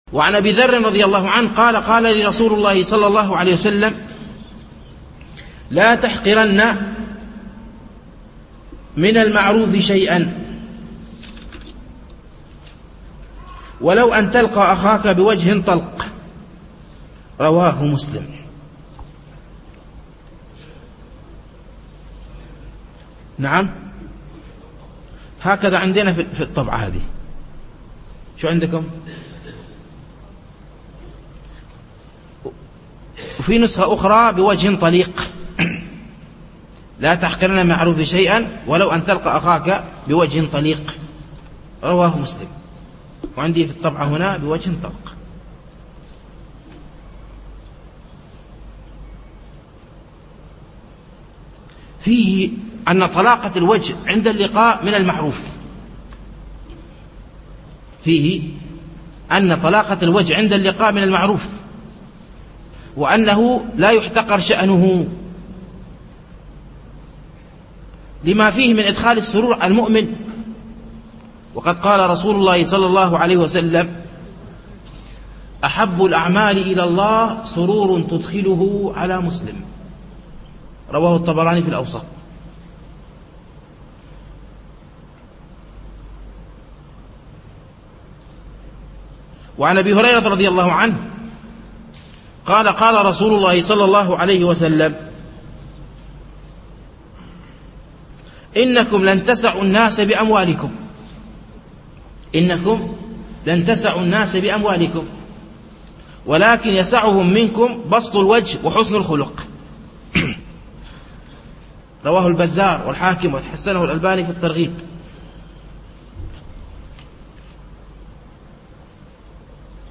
شرح حديث ((لا تَحقِرن من المعروف شيئًا، ولو أن تلقى أخاك بوجه طَلق))